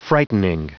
Prononciation du mot frightening en anglais (fichier audio)
Prononciation du mot : frightening